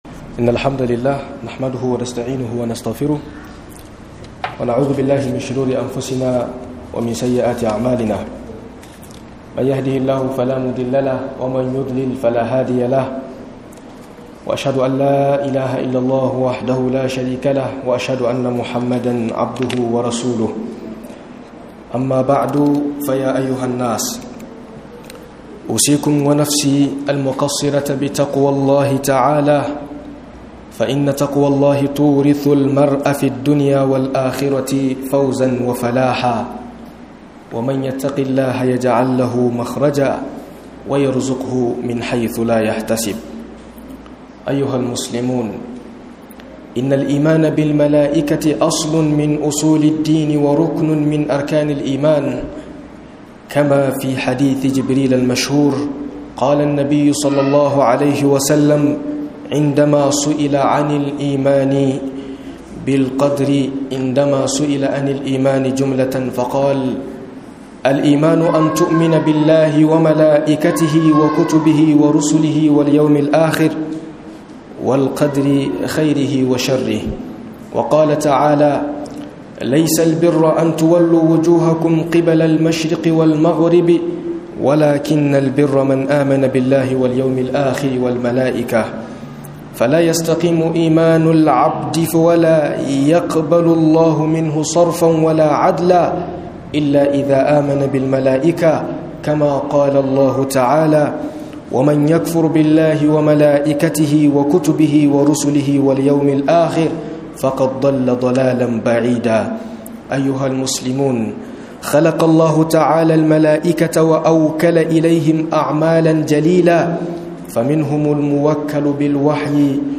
Imani da Mala'iku - MUHADARA